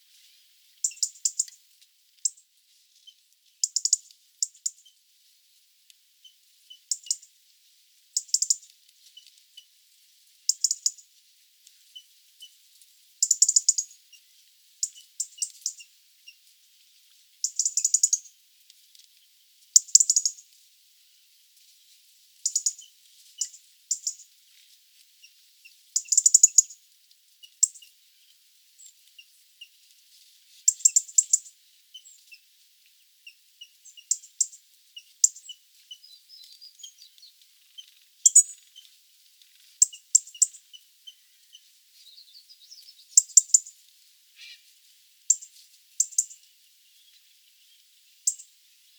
Erithacus rubecula - Robin - Pettirosso